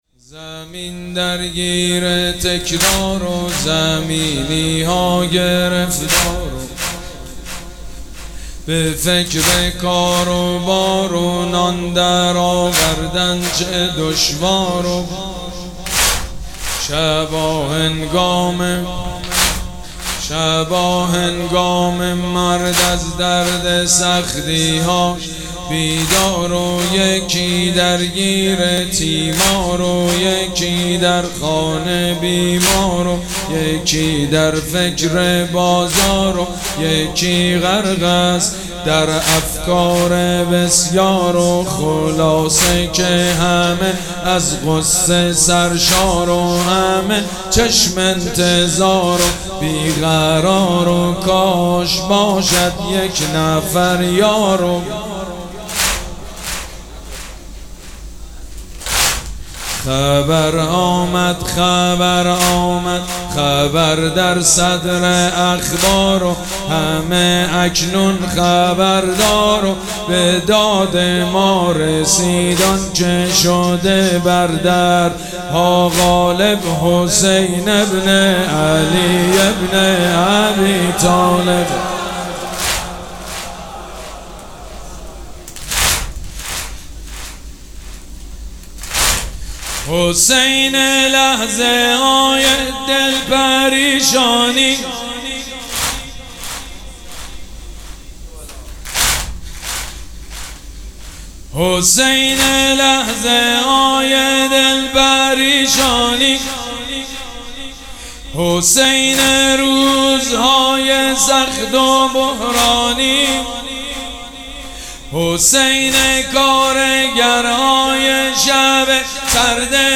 مراسم عزاداری شب هفتم محرم الحرام ۱۴۴۷
حاج سید مجید بنی فاطمه